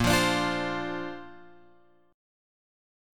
A#add9 chord {6 8 x 7 6 8} chord